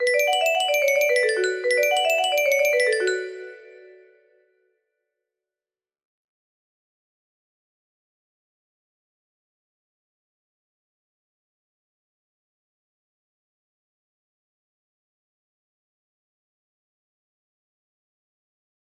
La pasión según san Mateo 2 music box melody